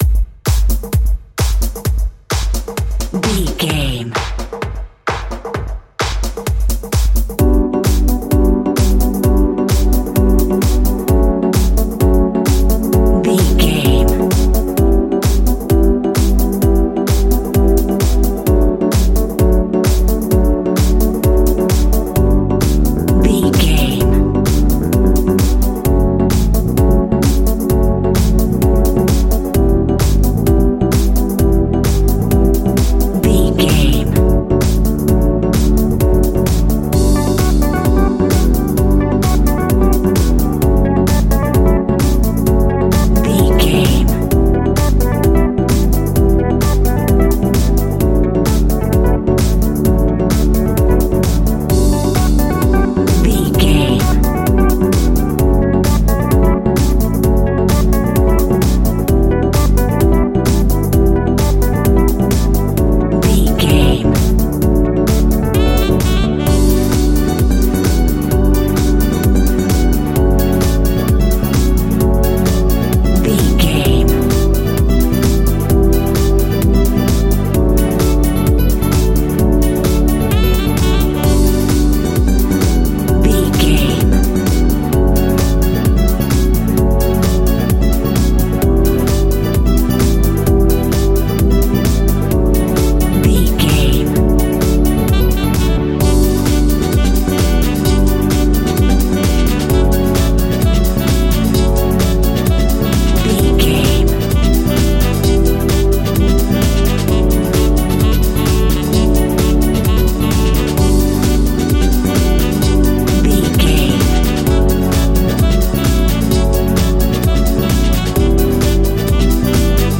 Funk Disco Electronica.
Ionian/Major
D
funky
groovy
uplifting
energetic
cheerful/happy
synthesiser
bass guitar
drums
strings
saxophone
piano
electric piano
funky house
nu disco
upbeat
instrumentals
funky guitar
synth bass